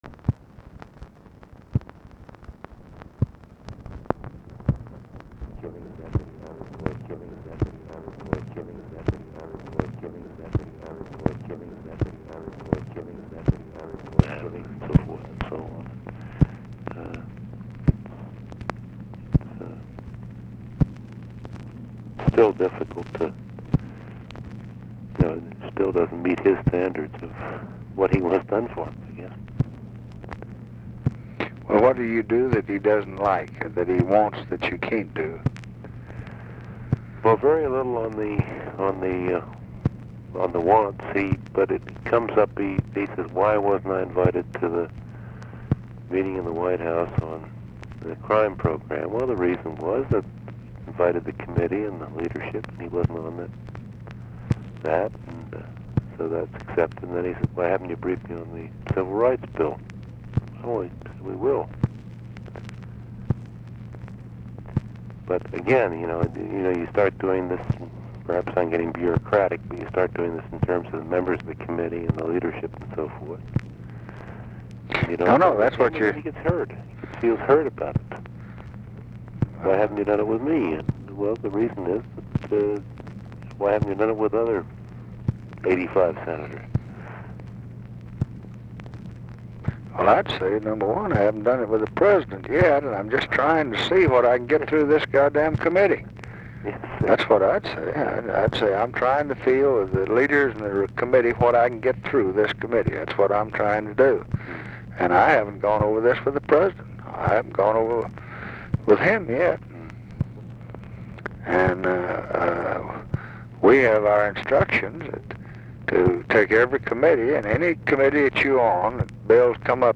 Conversation with NICHOLAS KATZENBACH, March 18, 1966
Secret White House Tapes